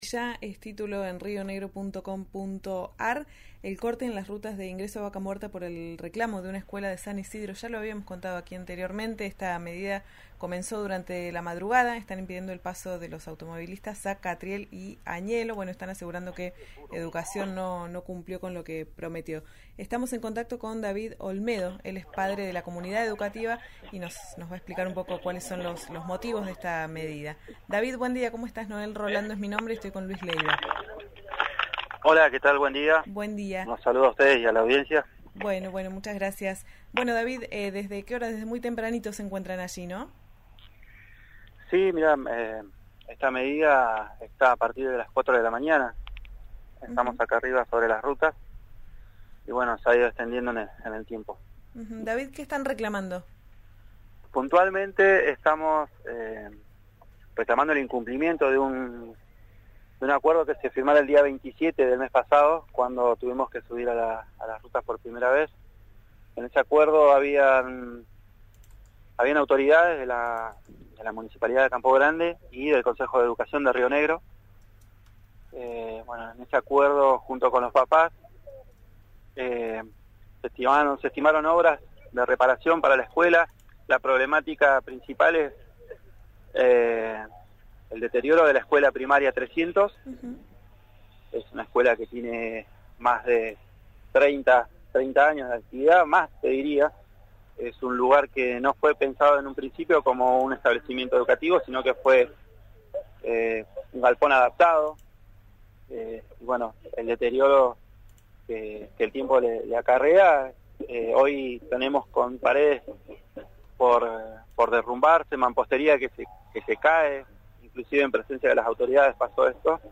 padre de la comunidad educativa, en «Ya es Tiempo» por RÍO NEGRO RADIO